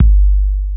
KIK XC.BDR01.wav